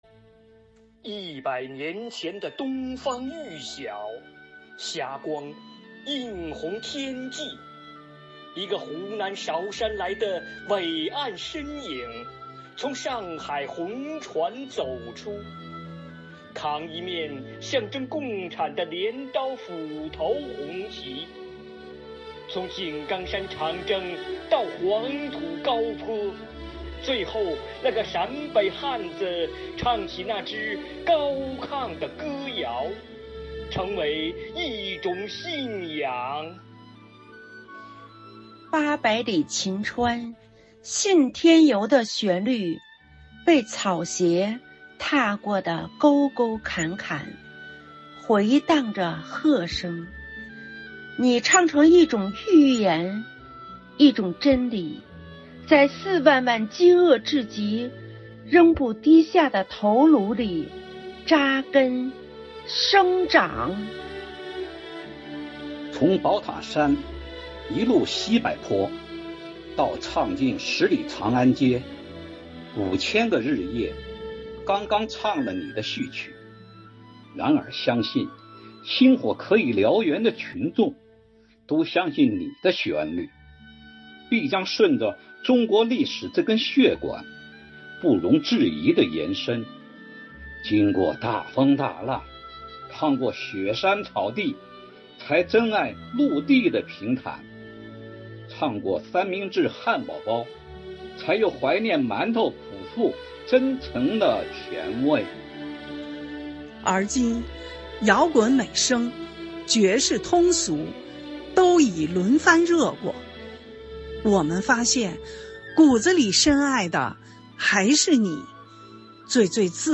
《东方红颂》合诵：
朗诵《东方红颂》.mp3